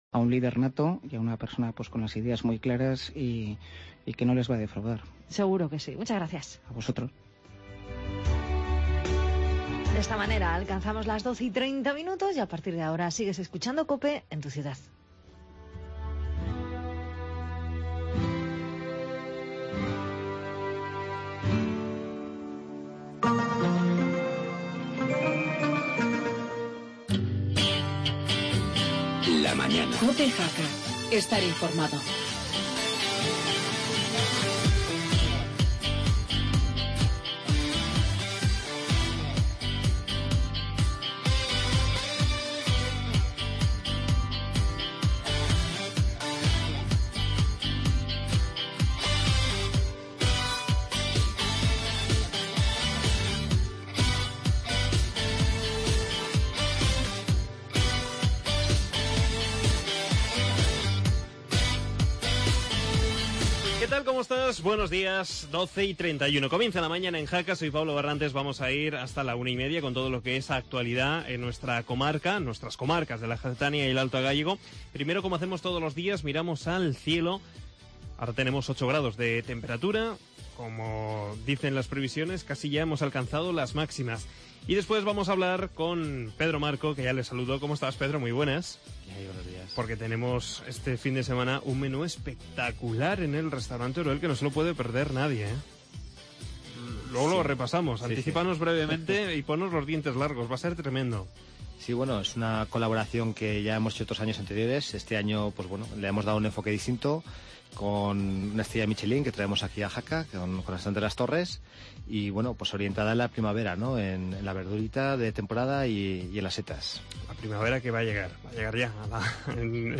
tertulia